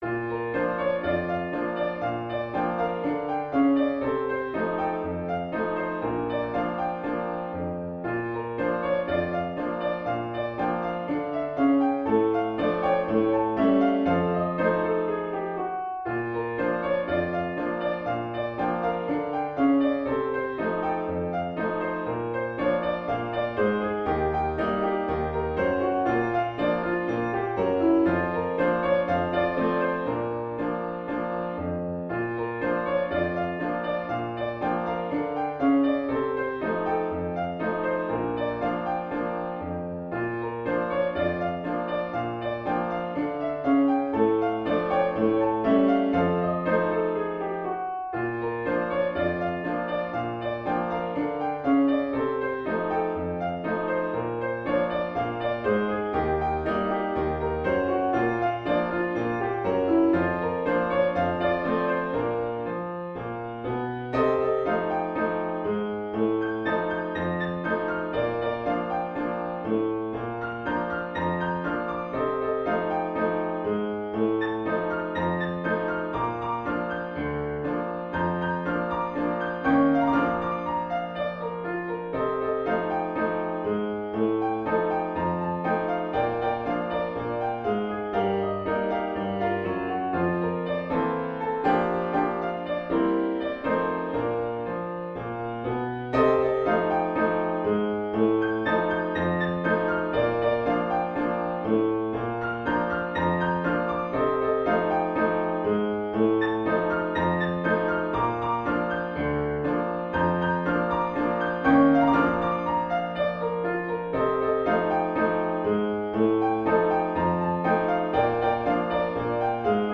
A Lyrical rag with mostly classic ragtime sensibilities.